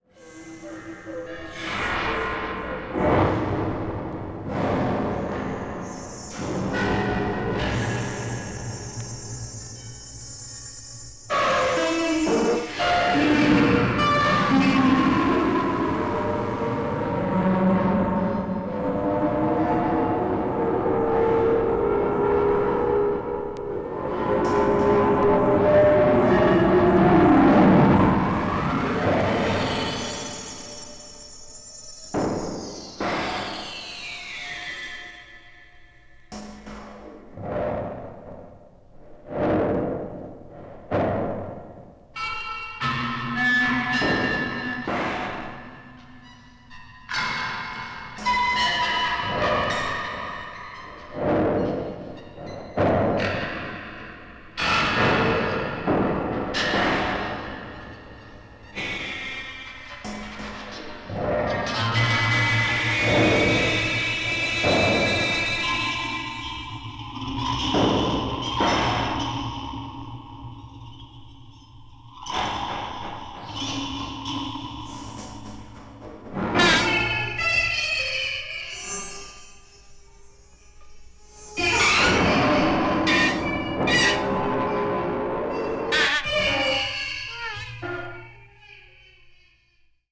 media : EX+/EX+(わずかにチリノイズが入る箇所あり)